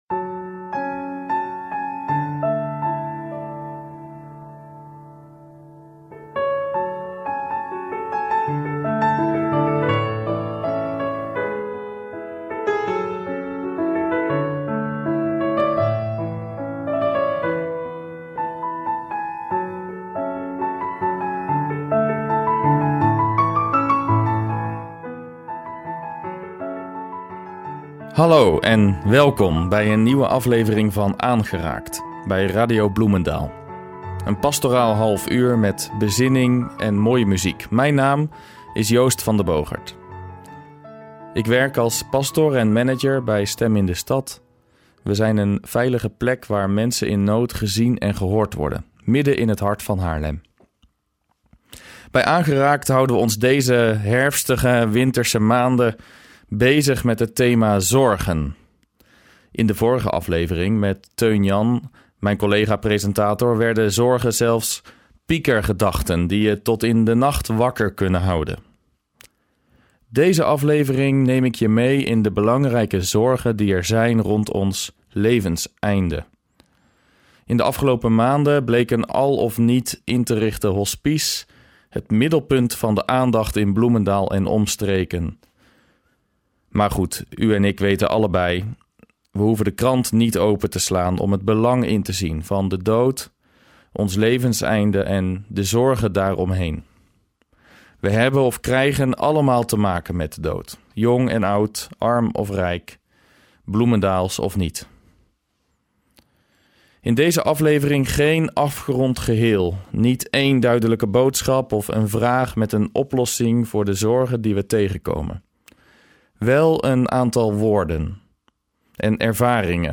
Een gesprek rond de zorgen, het rouwen, het zoeken rond het levenseinde, maar ook over het ‘samen’, het ‘licht dat niet dooft’ en de ‘liefde die blijft’.